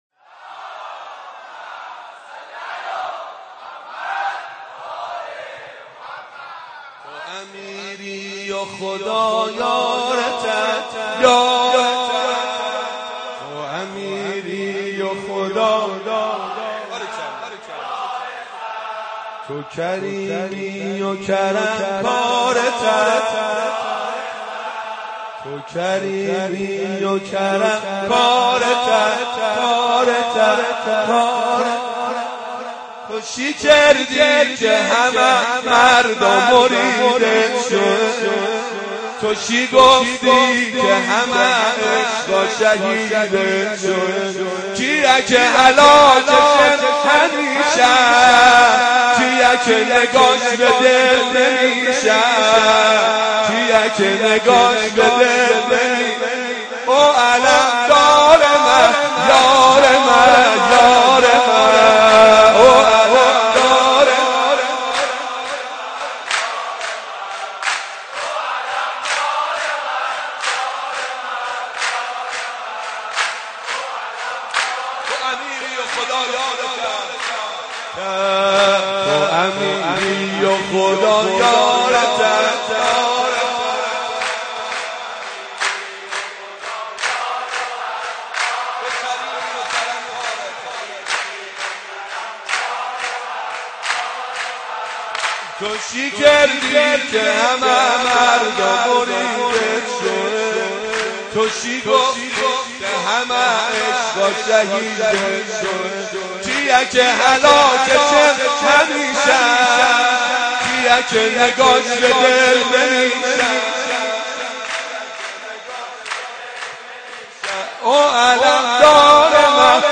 مداحی لری
به گویش لری در ستایش حضرت قمربنی هاشم(ع)